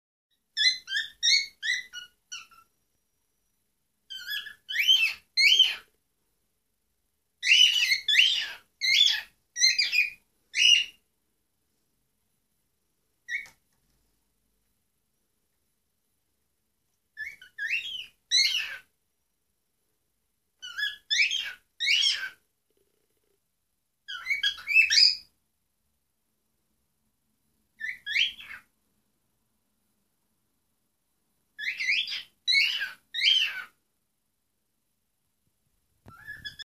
Unsere Nymphensittiche
Zu unserer Überraschung kann Fritzi schön singen (Fritzi1.mp3,